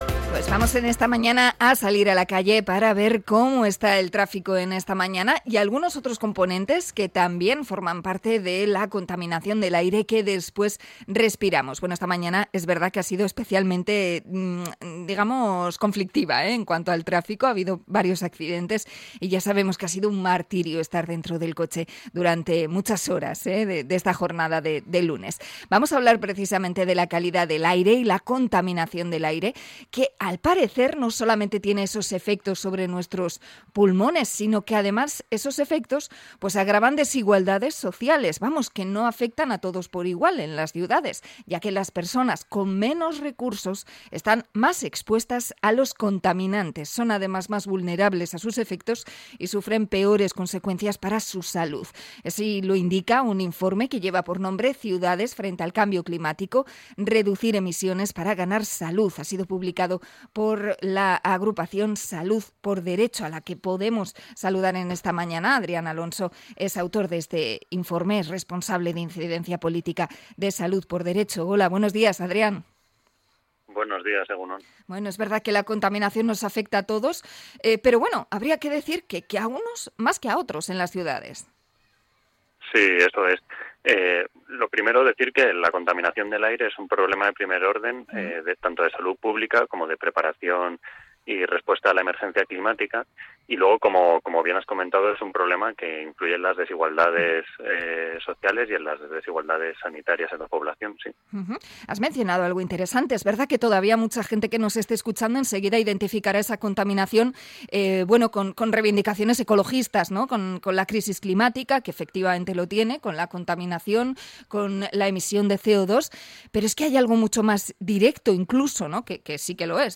Entrevista sobre el informe de Salud por Derecho